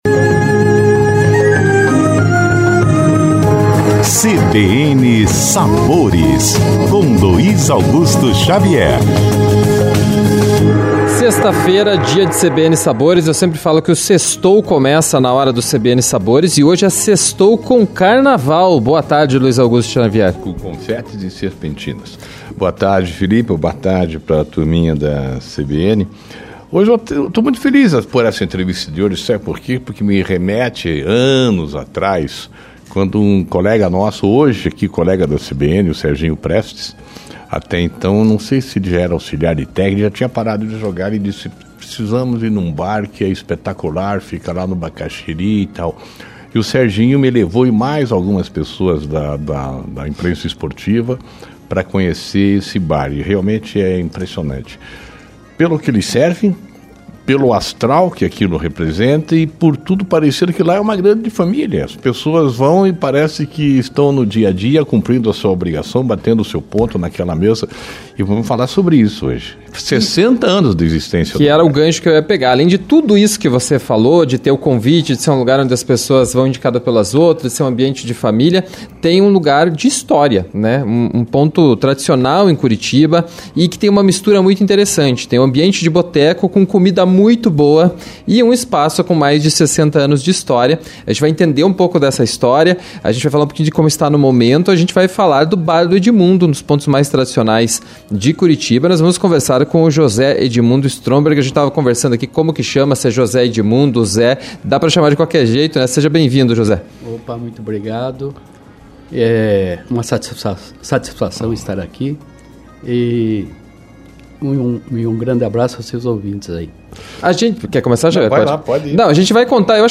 Confira o bate-papo saboroso: